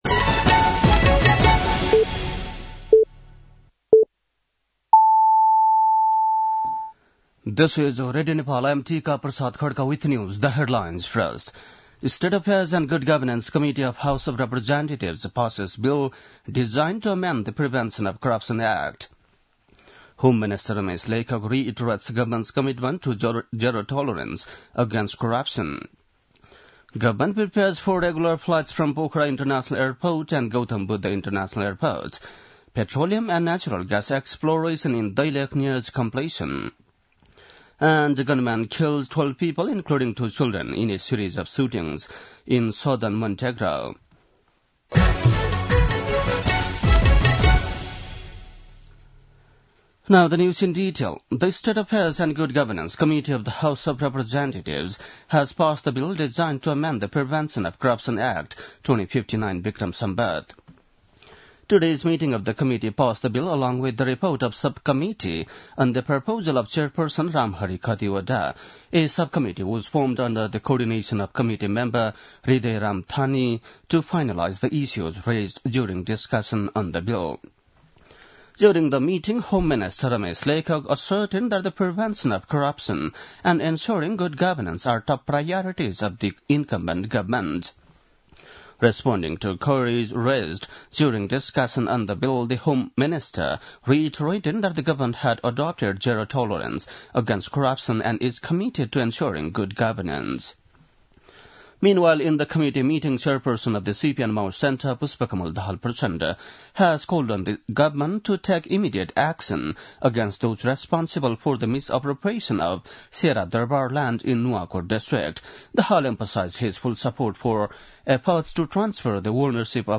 बेलुकी ८ बजेको अङ्ग्रेजी समाचार : १९ पुष , २०८१
8-pm-nepali-news-9-18.mp3